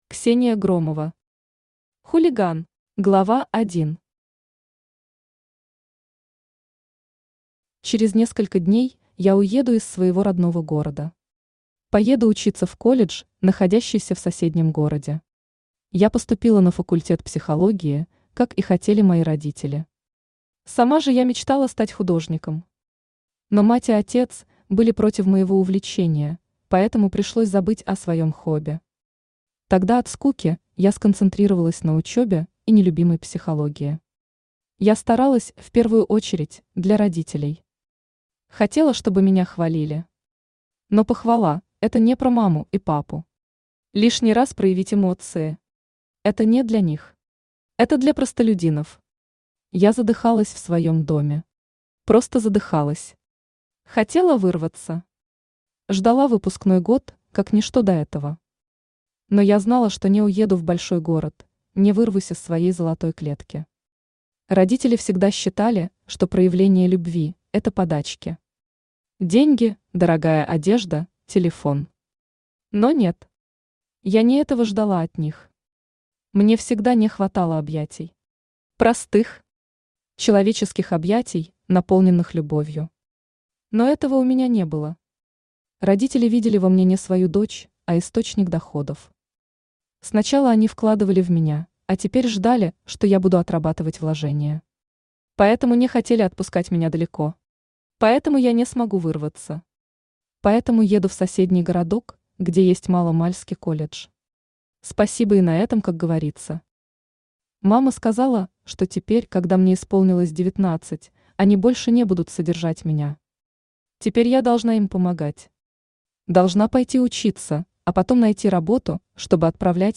Аудиокнига Хулиган | Библиотека аудиокниг
Aудиокнига Хулиган Автор Ксения Громова Читает аудиокнигу Авточтец ЛитРес.